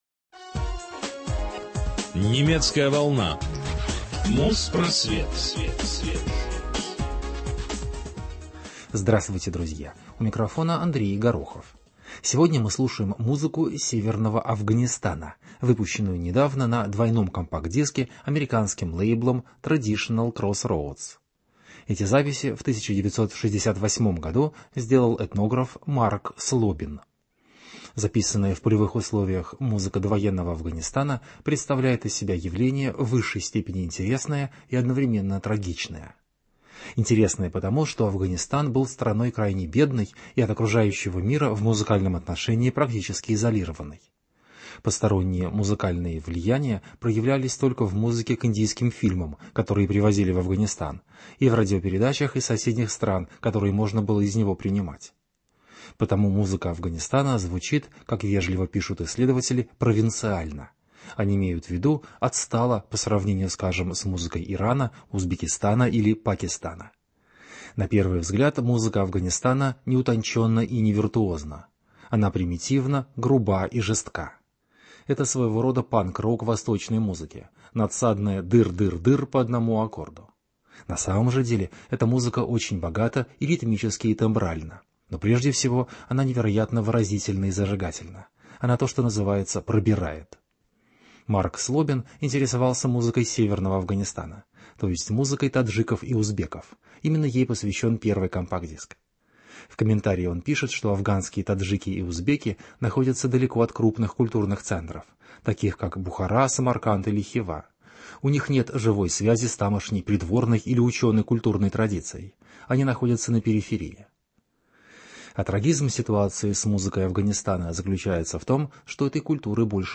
Довоенная музыка северного Афганистана. Записанная в полевых условиях музыка 1958 года.